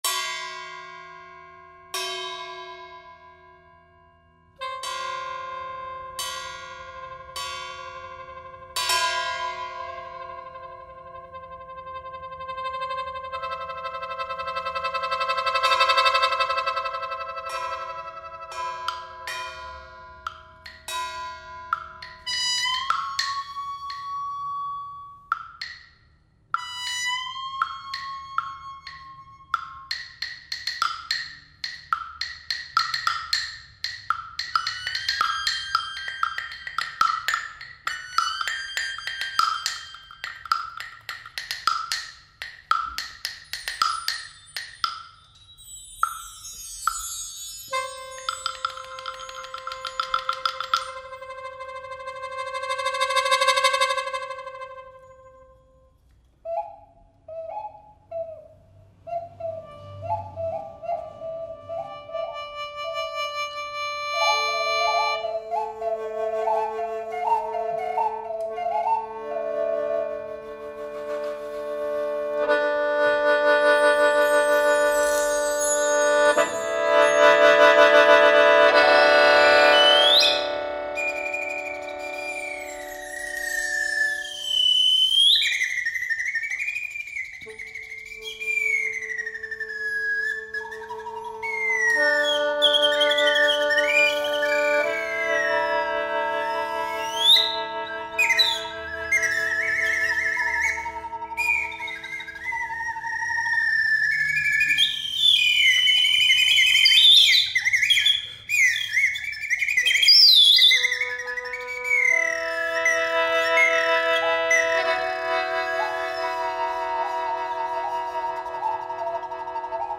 I respond to these questions with musical sounds in three sections of this piece: Worry, Imagine, and Hope. The music is an improvisation in response to a set of pre-recorded sounds.
The sheng (Chinese mouth organ)